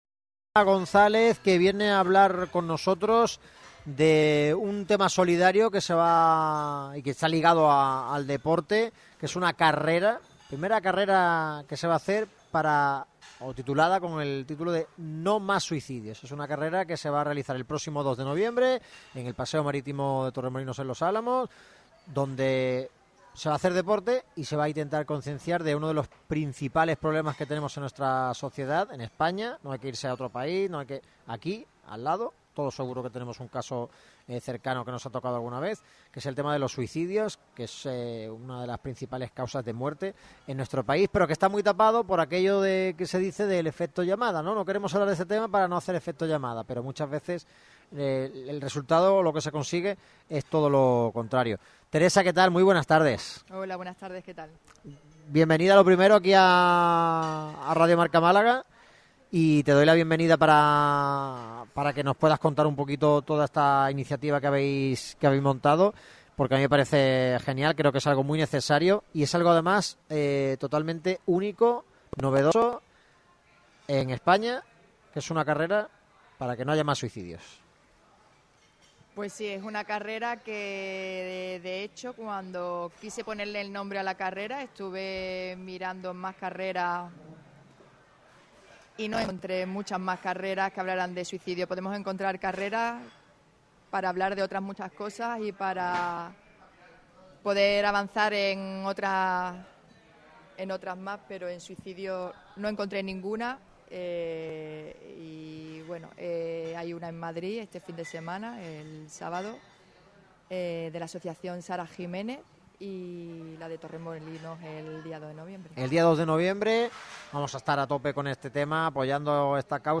ha pasado por los micrófonos de Radio MARCA Málaga para explicar el propósito de este evento pionero: